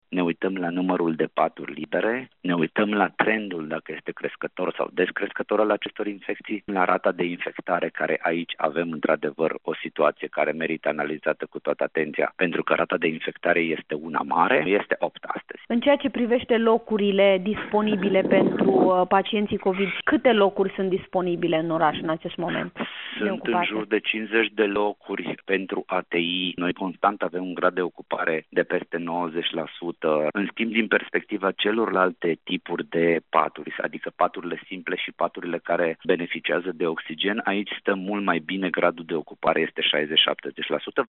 De partea cealaltă, directorul DSP Timiș, Flavius Cioca, spune că instituția sa analizează constant posibilitatea carantinării Timișoarei, însă deocamdată, nu sunt îndeplinite toate criteriile impuse de lege, explică el:
19nov-13-interviu-dir-DSP-TM.mp3